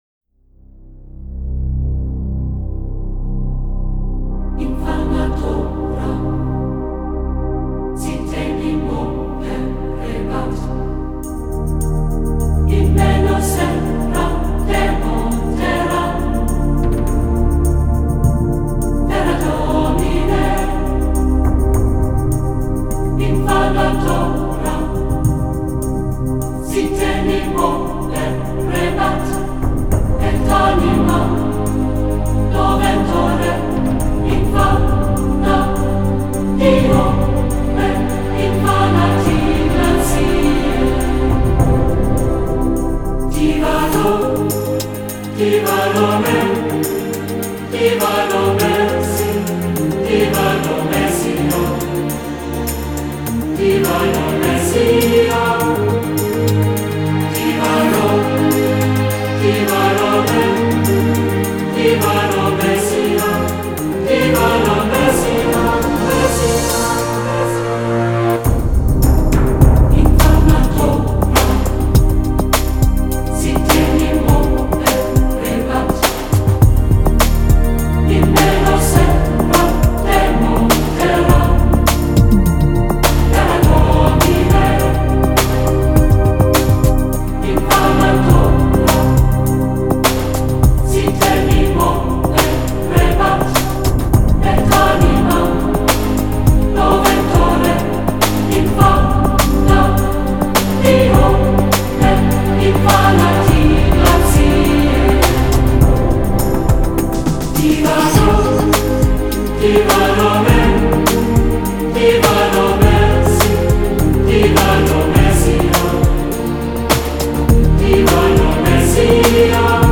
Нью-эйдж